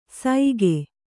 ♪ saige